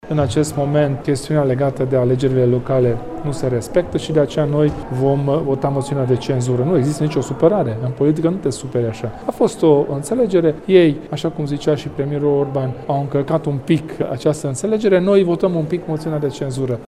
Liderul formaţiunii, Kelemen Hunor, spune că este modalitatea prin care Uniunea vrea să-i sancţioneze pe liberali pentru decizia de a reintroduce alegerea primarilor în două tururi, încălcând astfel înţelegerea de a nu modifica legislaţia electorală înainte de alegerile locale: